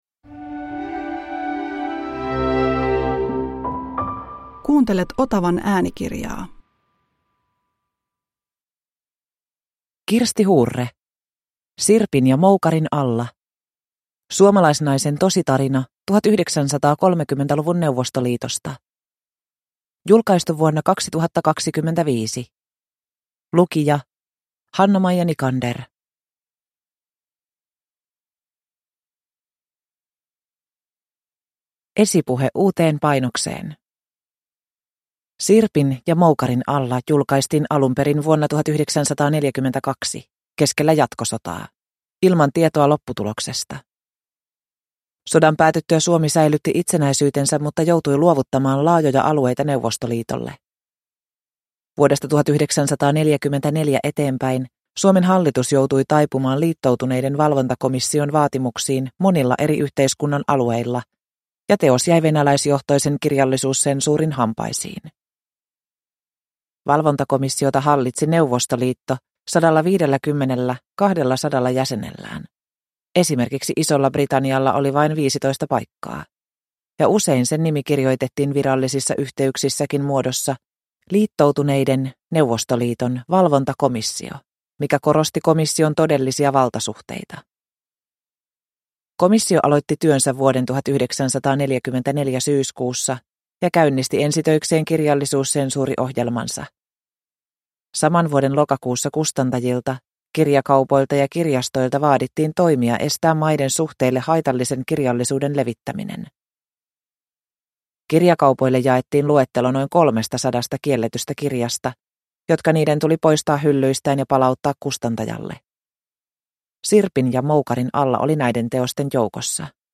Sirpin ja moukarin alla – Ljudbok